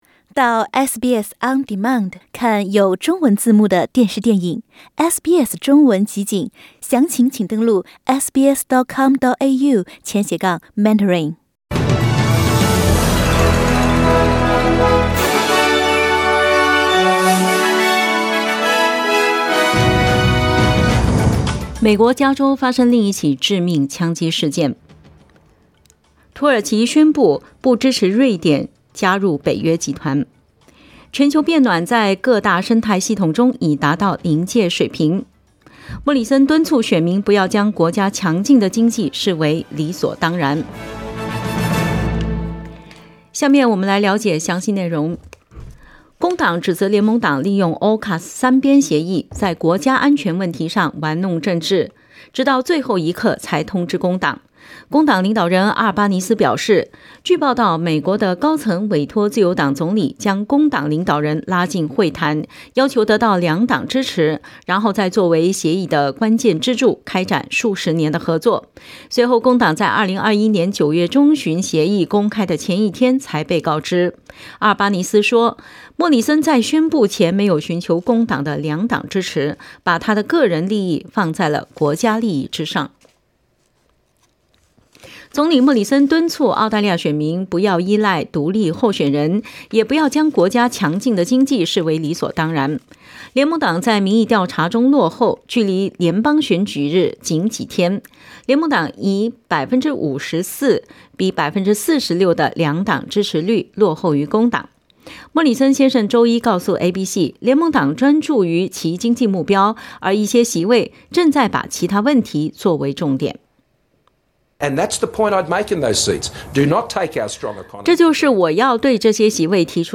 SBS早新闻（5月17日）
SBS Mandarin morning news Source: Getty Images